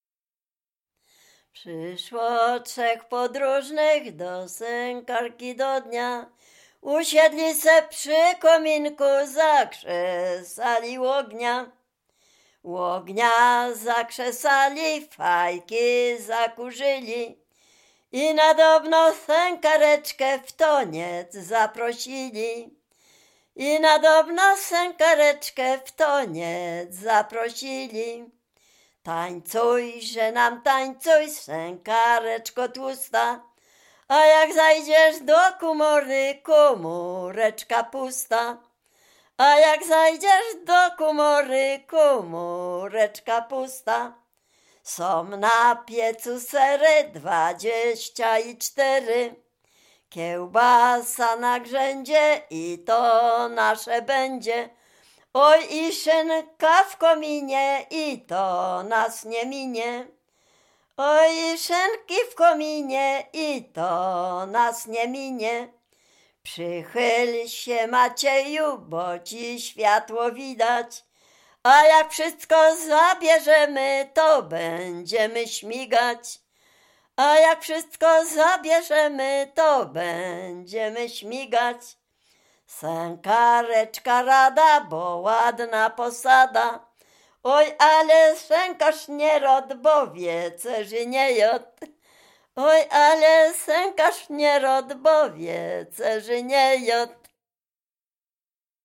Dolny Śląsk, powiat bolesławiecki, gmina Osiecznica, wieś Przejęsław
Kolęda
ballada kolęda